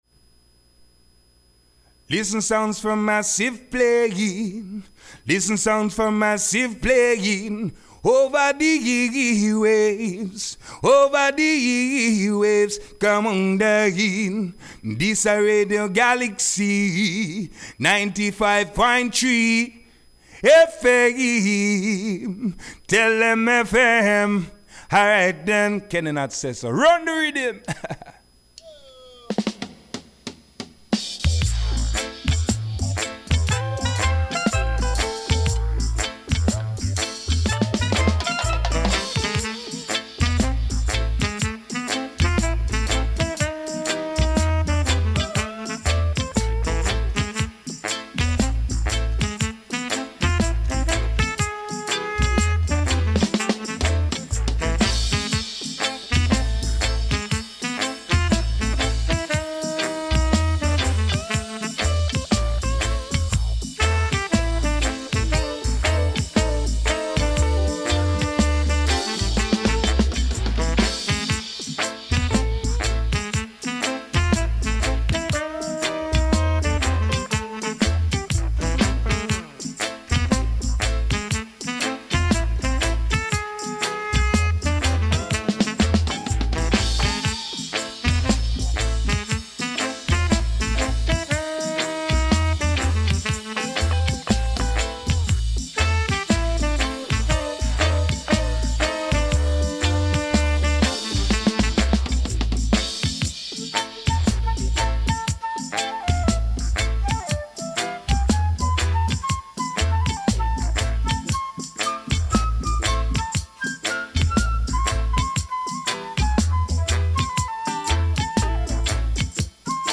reggaephonique
enregistré hier soir dans les studios